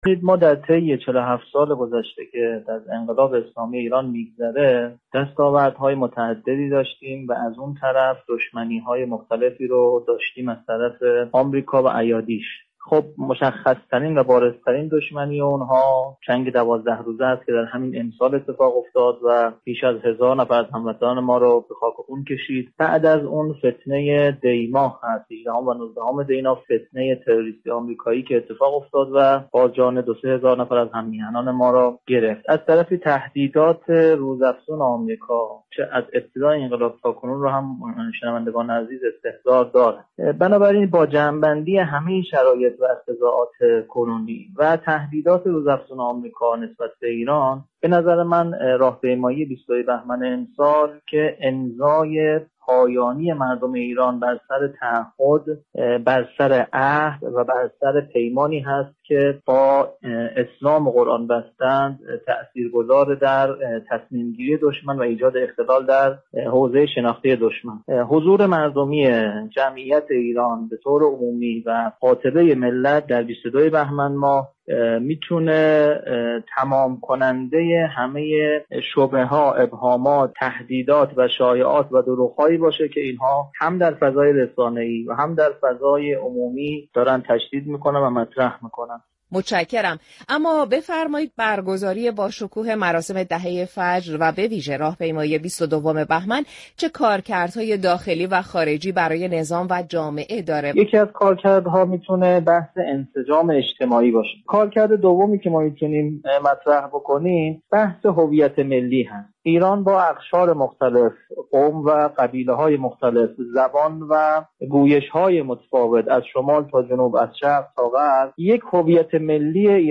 گفتگوی اختصاصی